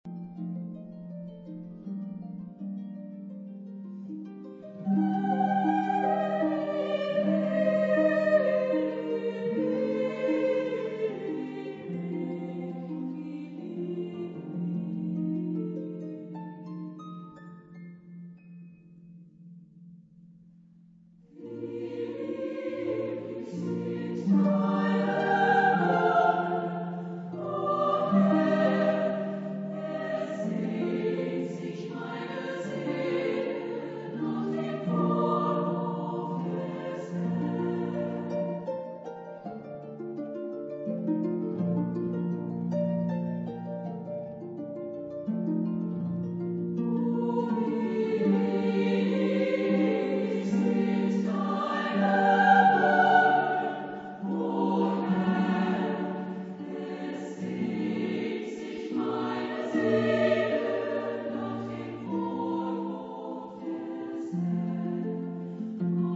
Genre-Style-Forme : Sacré ; Hymne (sacré) ; Romantique
Caractère de la pièce : pieux
Type de choeur : SSAA  (4 voix égales de femmes )
Instrumentation : Piano  (1 partie(s) instrumentale(s))
Tonalité : si bémol majeur